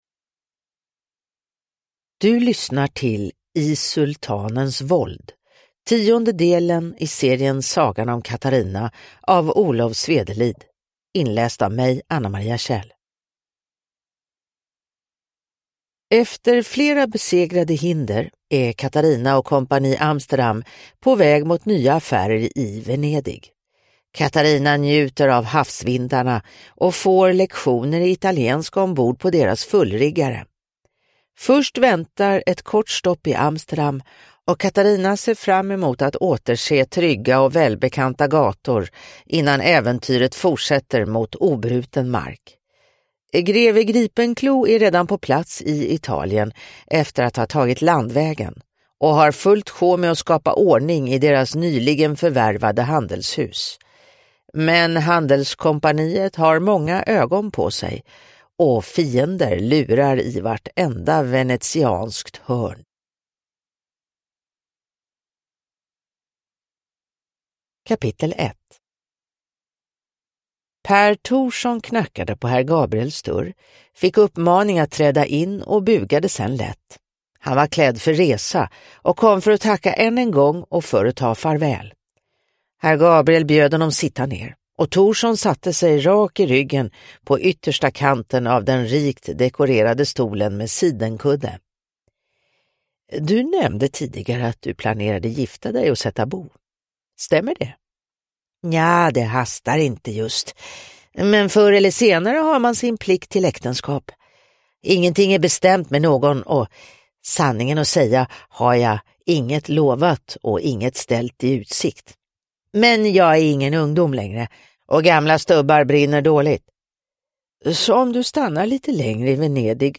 I sultanens våld – Ljudbok – Laddas ner